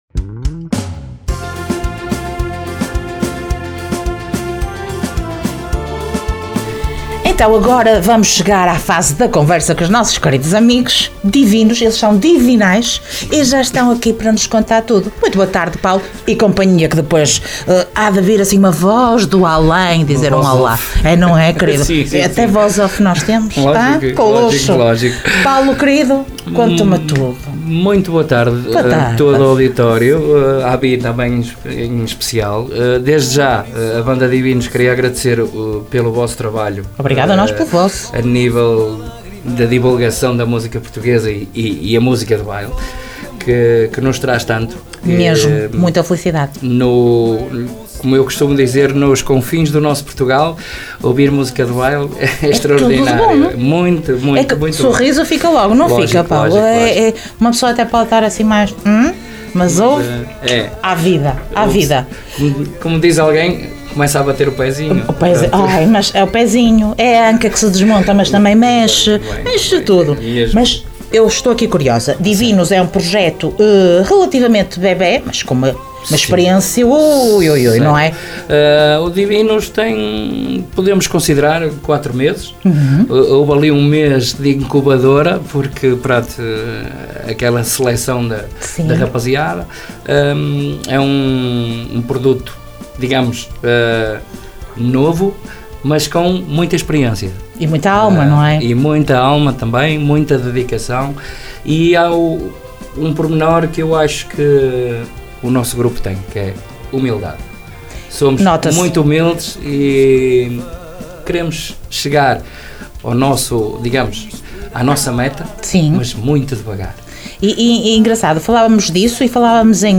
Entrevista Banda Divinu`s